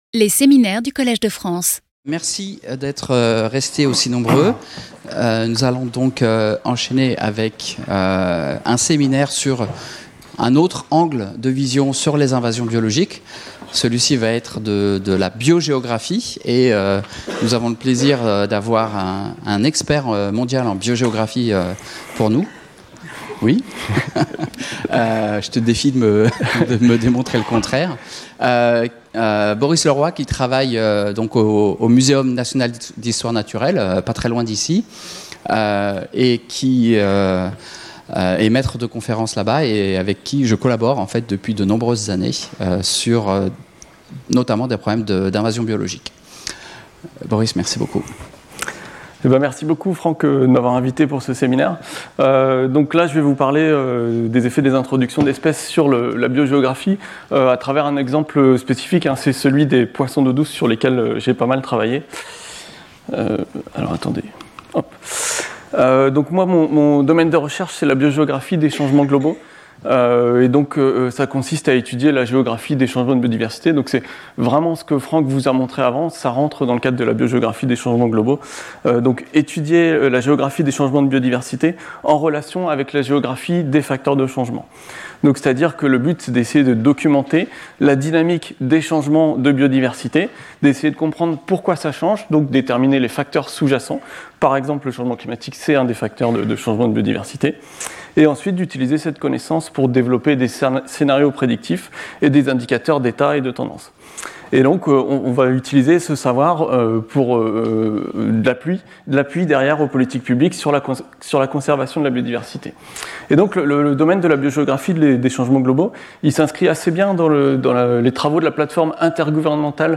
In this seminar, we'll examine these upheavals and their consequences with a journey through time to the past, from the present, and a peek into the future.